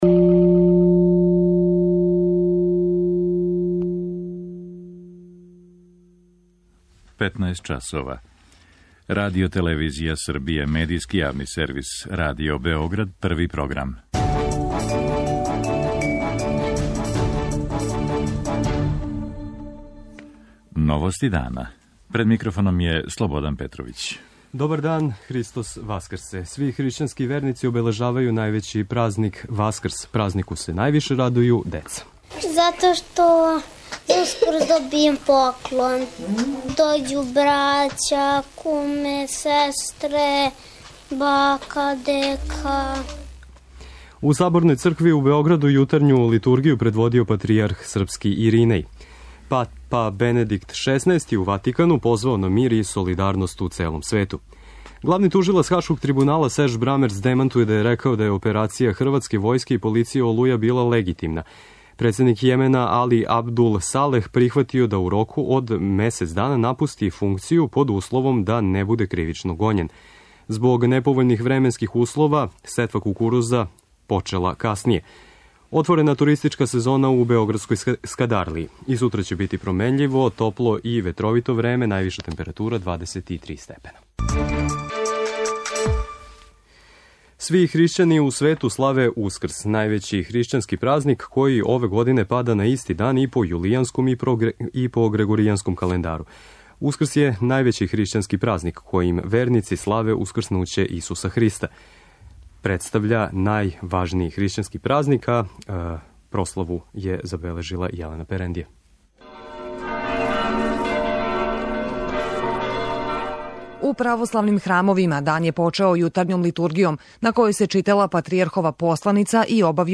Радио Београд 1, 15:00.